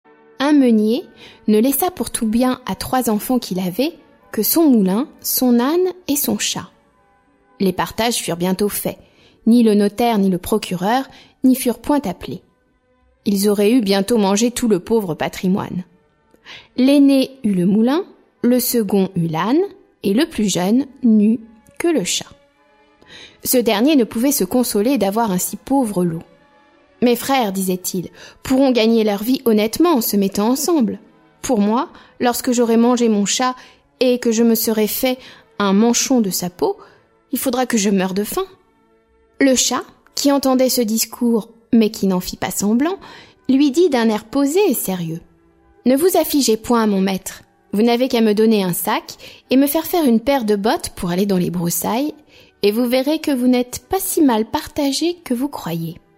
Conte de Charles Perrault Musique : Offenbach (Contes) et Wagner (Tannhauser, Ouverture)